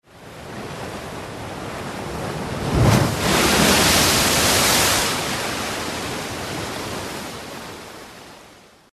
Звуки моря, волн